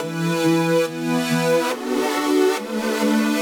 Index of /musicradar/french-house-chillout-samples/140bpm/Instruments
FHC_Pad C_140-E.wav